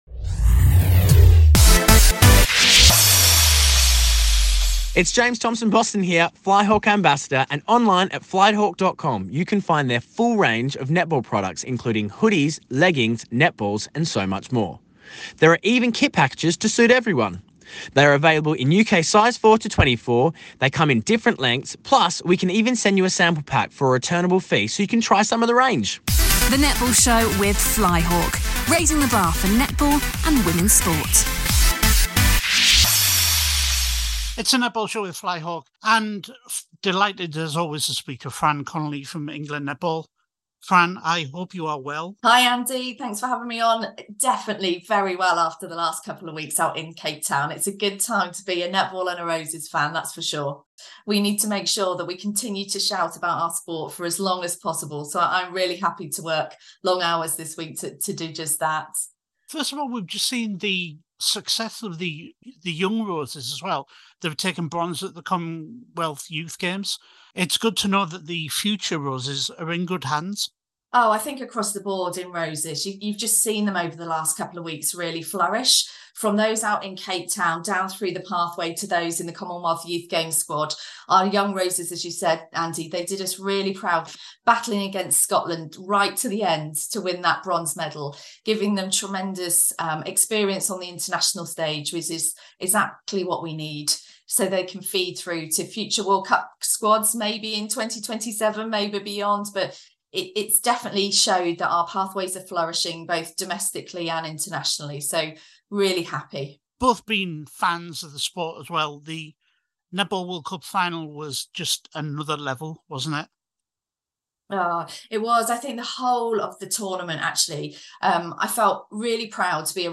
The first part of our chat